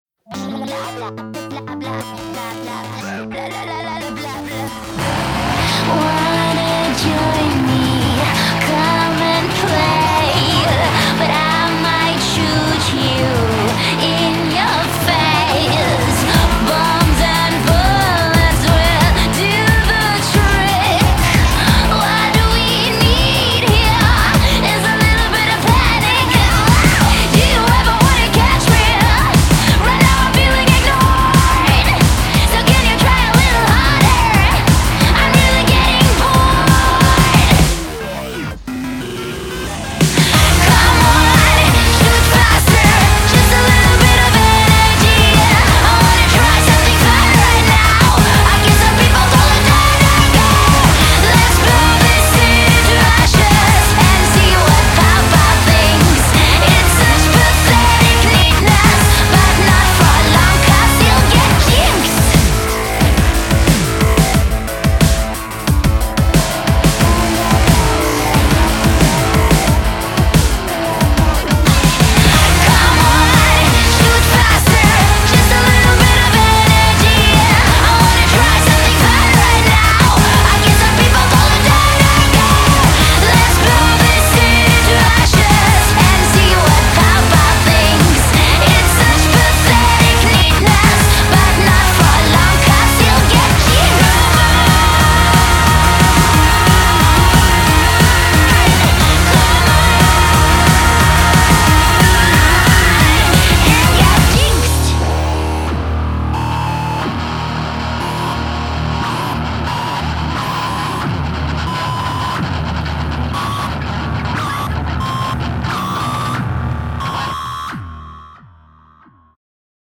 BPM180
Audio QualityPerfect (High Quality)